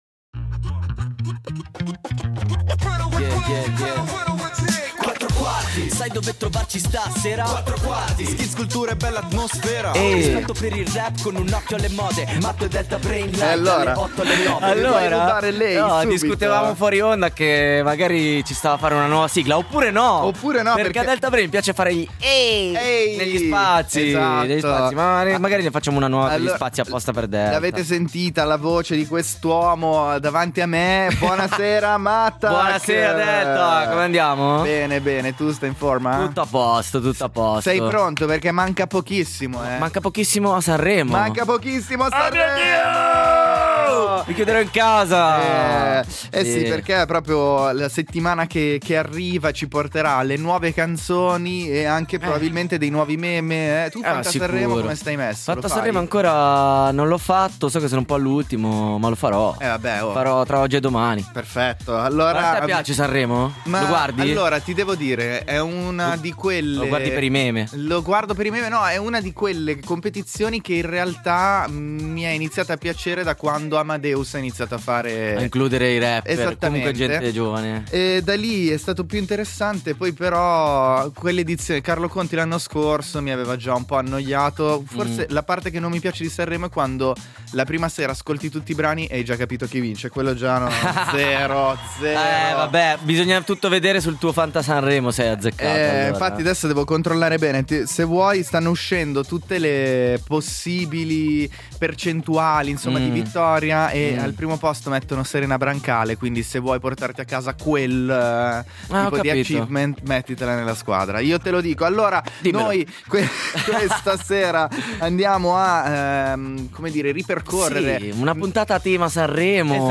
RAP / HIP-HOP